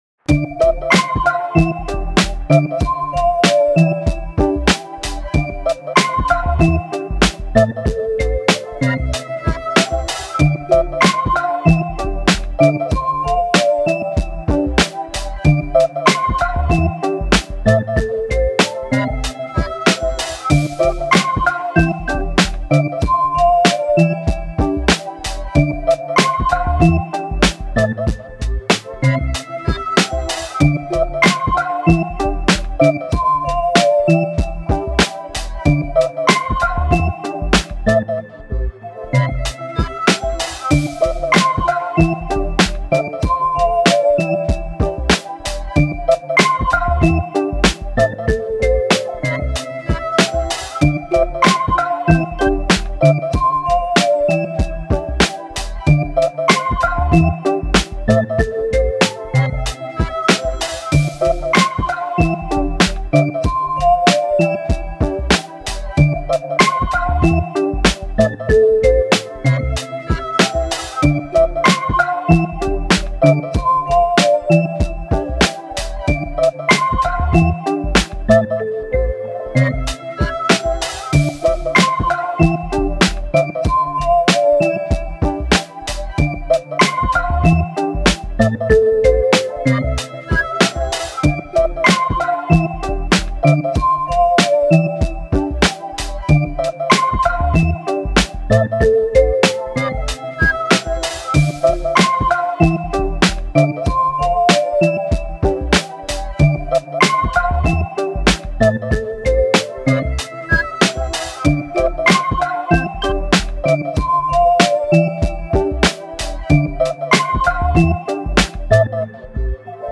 かっこいい オルタナティヴ フリーBGM